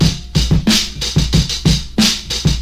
Free breakbeat sample - kick tuned to the E note. Loudest frequency: 2183Hz
• 92 Bpm Drum Groove E Key.wav
92-bpm-drum-groove-e-key-kh0.wav